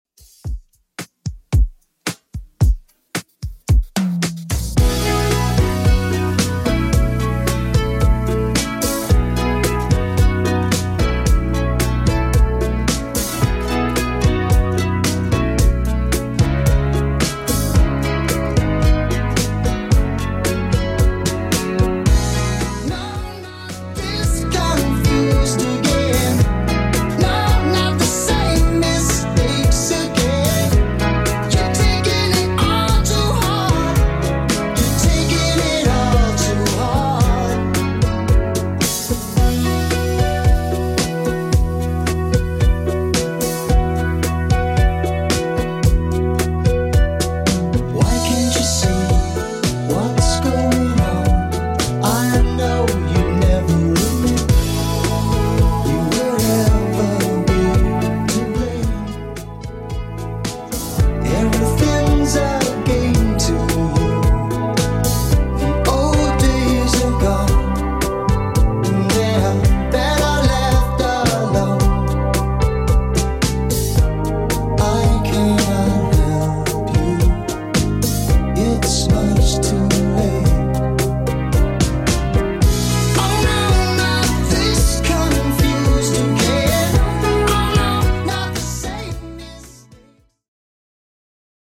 Genre: 70's
BPM: 102